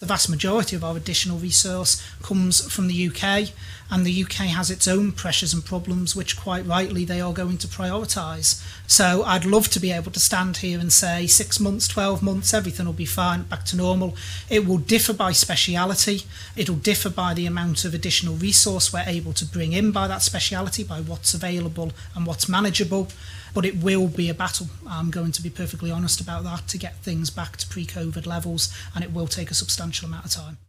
Health Minister David Ashford made the comments at the latest coronavirus media conference.